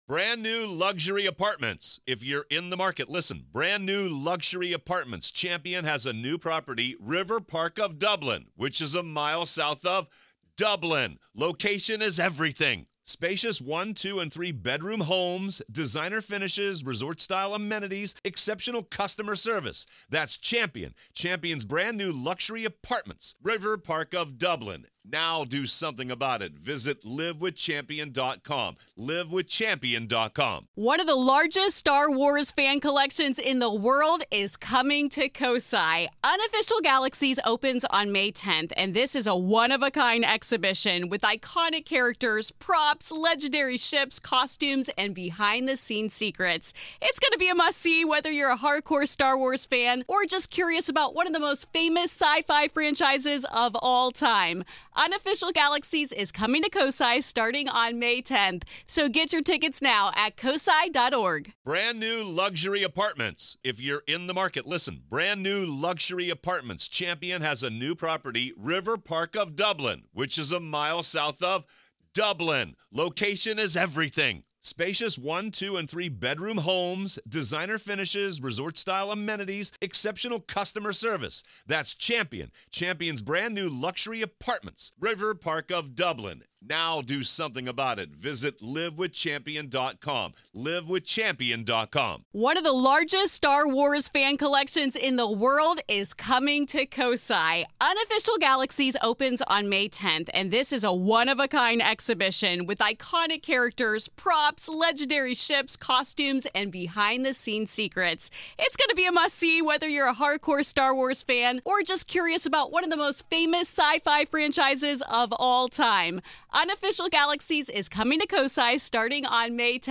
Are they a residual glimpse back in time, or are they conscious entities still taking part in the life activities they once knew so well? This is Part Two of our conversation.